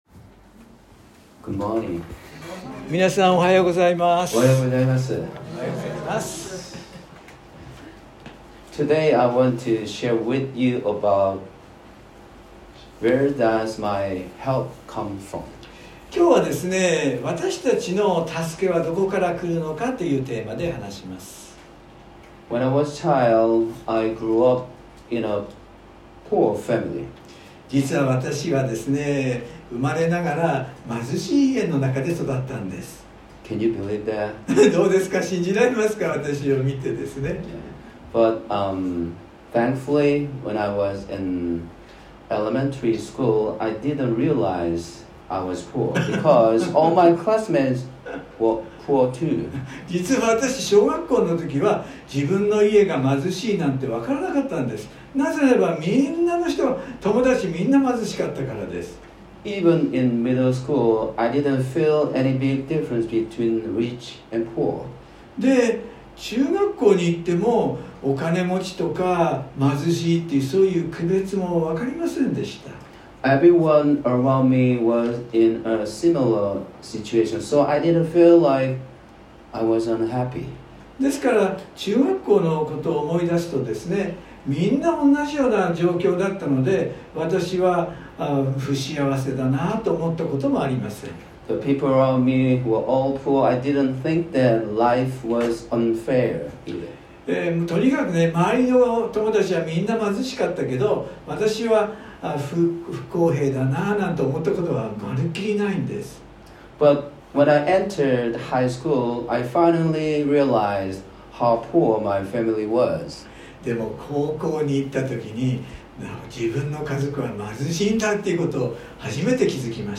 （日曜礼拝録音） 【iPhoneで聞けない方はiOSのアップデートをして下さい】 原稿は英語のみになります。
（上のバーから聞けない方は青いボタンから） iPhone 宣教師メッセージ 礼拝メッセージ シェアする X Facebook はてブ LINE コピー インターナショナル・バイブル・フェローシップ